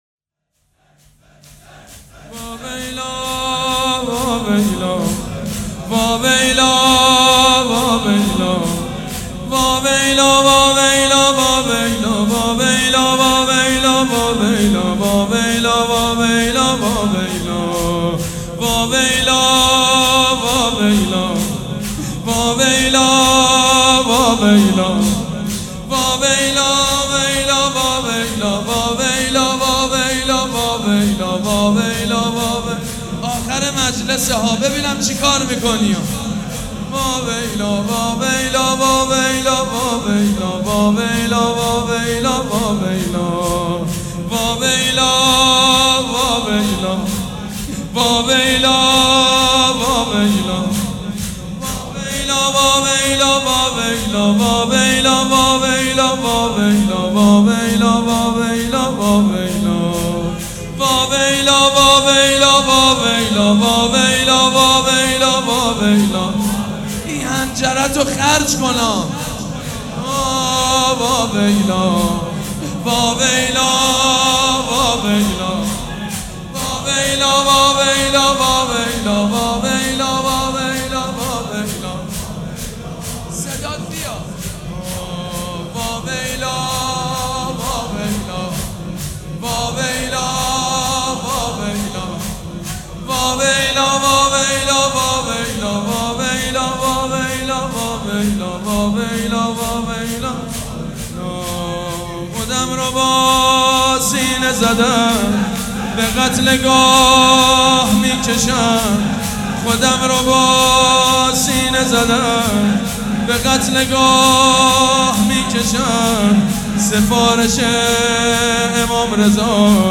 سبک اثــر شور
مراسم عزاداری شب سوم